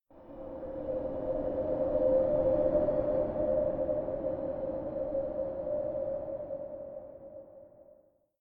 scpcb-godot/SFX/Ambient/Forest/ambient9.ogg at 8f5d2fcf9fe621baf3dc75e4253f63b56f8fd64b